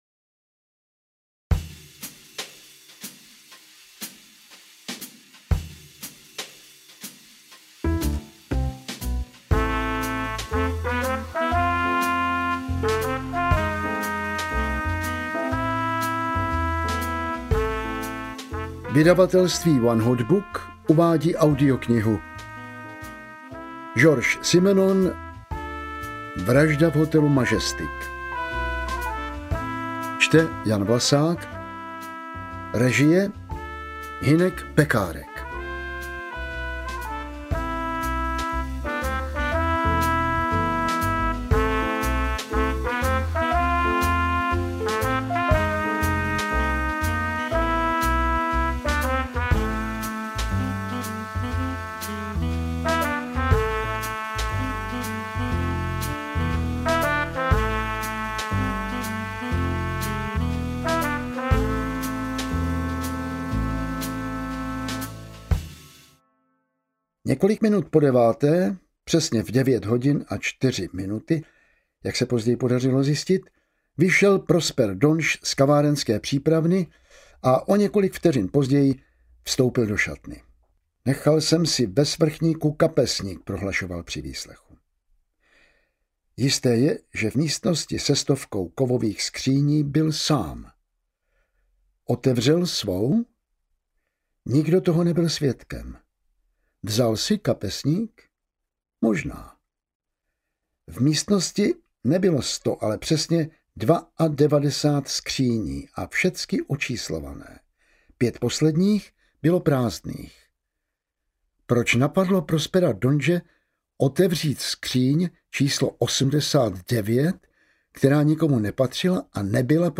Maigret: Vražda v hotelu Majestic audiokniha
Ukázka z knihy
• InterpretJan Vlasák